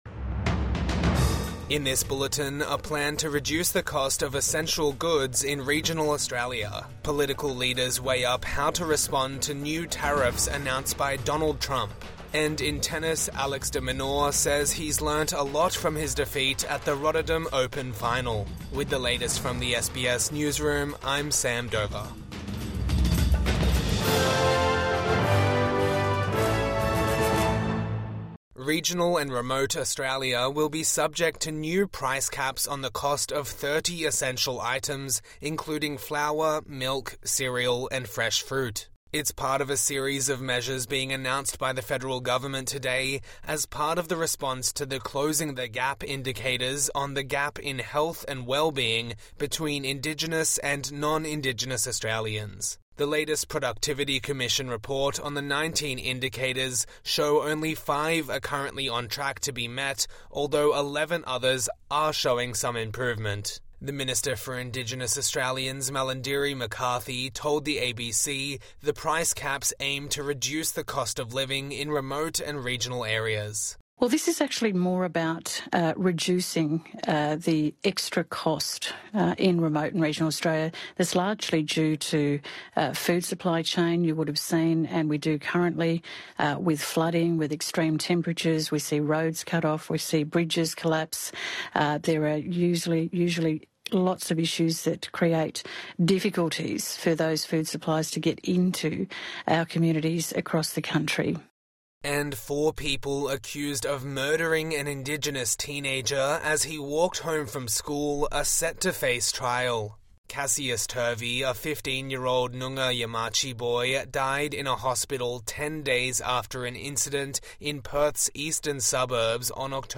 Midday News Bulletin 10 February 2025